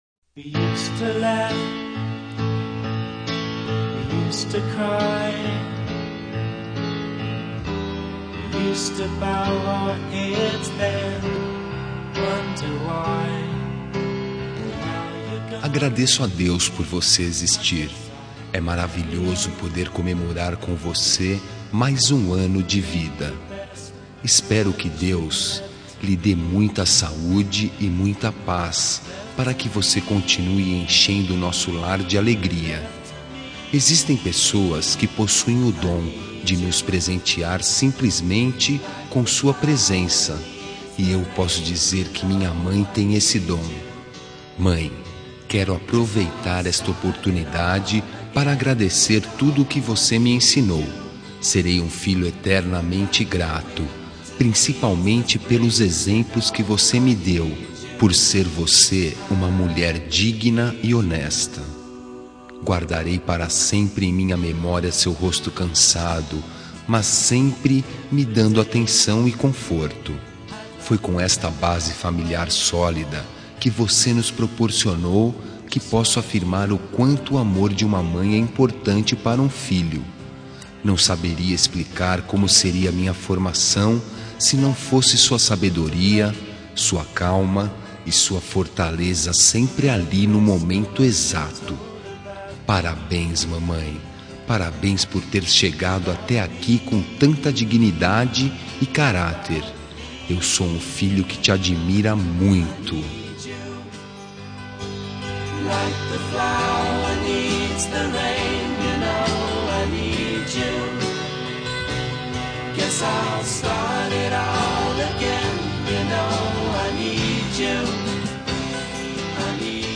Telemensagem de Aniversário de Mãe – Voz Masculina – Cód: 1429